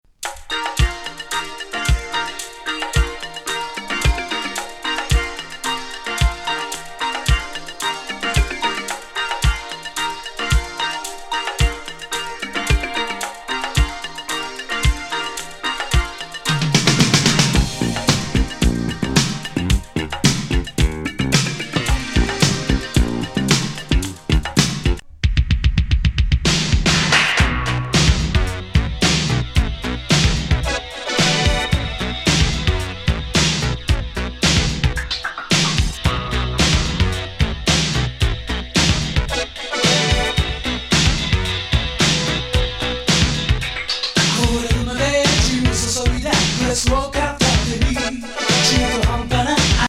ベロベロ・シンセもイカしたエイティーズ・エレクトロ・モダン・ファンク！
Japanese,Electric,Modern,Funk ♪ LISTEN LABEL/PRESS